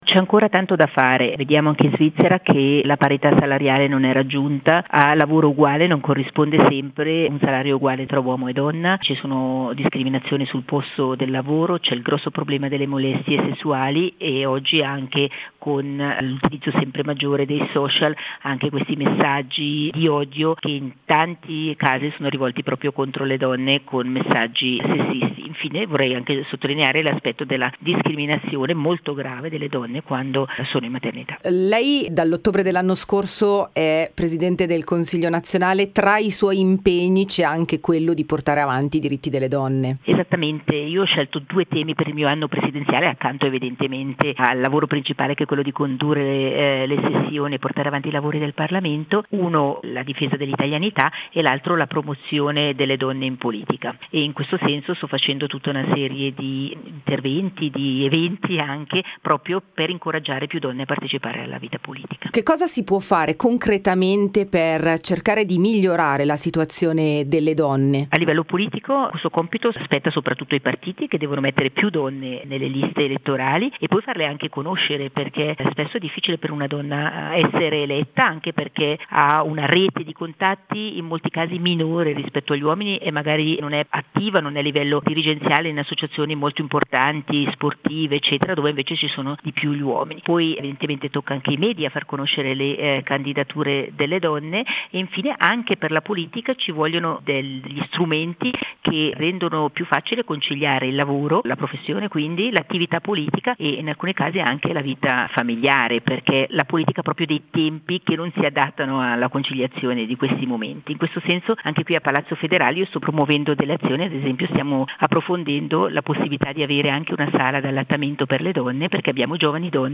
• Intervista Radio 3i: